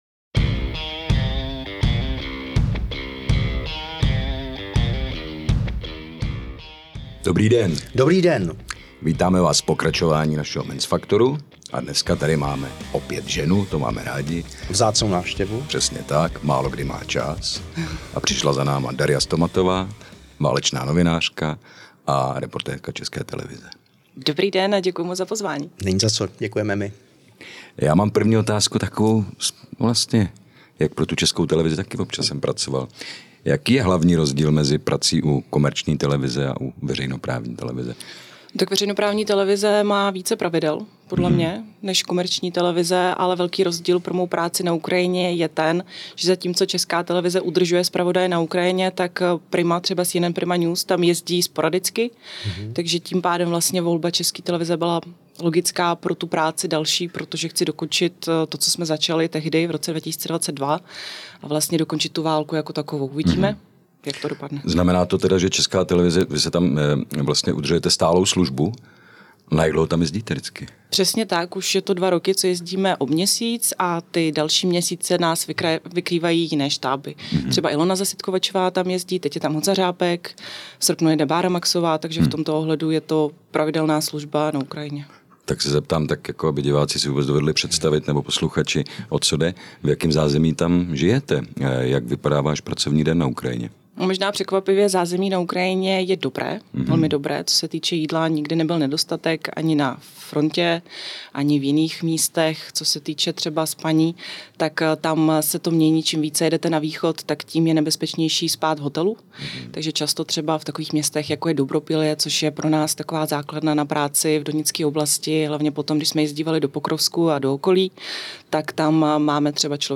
V rozhovoru pro podcast Men’s Factor mluvila otevřeně o životě na frontě, nebezpečí, dronové válce, emocích i důležitosti nestranného zpravodajství.